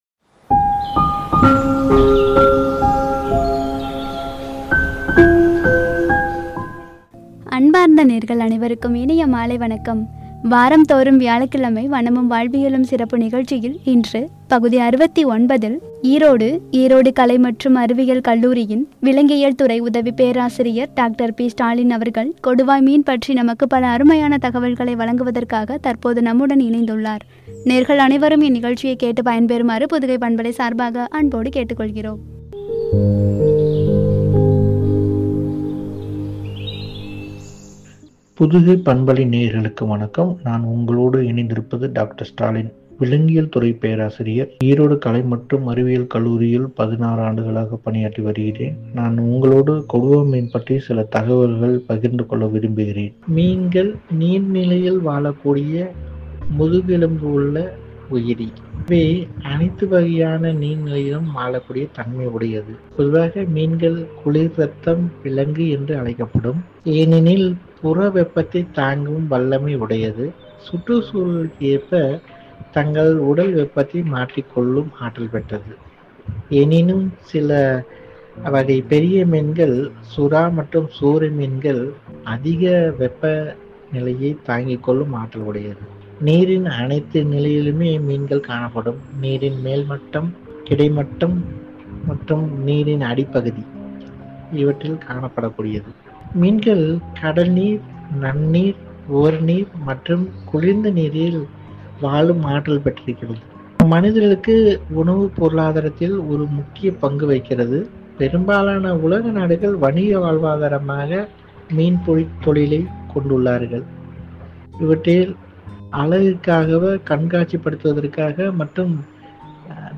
(வனமும், வாழ்வியலும்- பகுதி 69) “கொடுவா மீன்” என்ற தலைப்பில் வழங்கிய உரை.